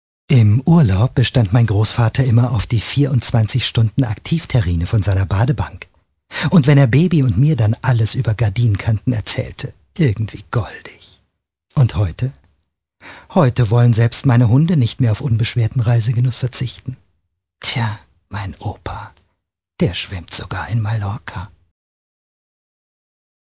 Stimmproben